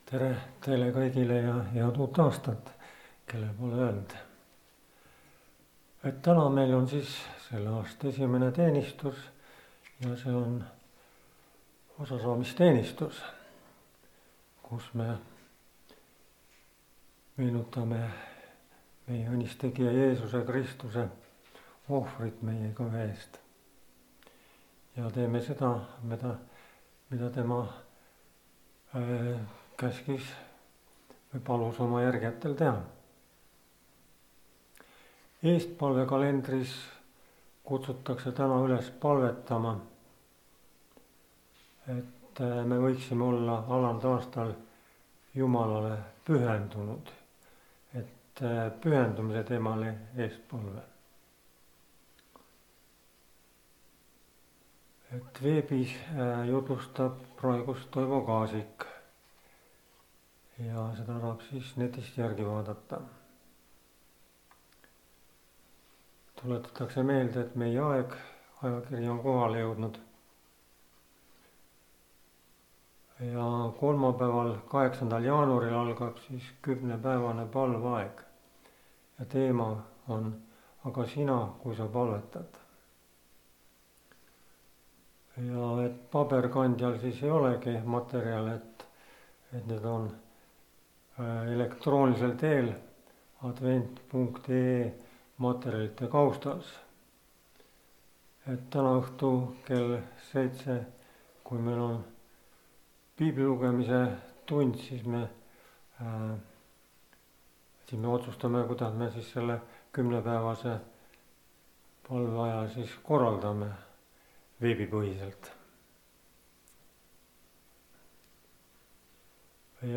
kategooria Audio / Koosolekute helisalvestused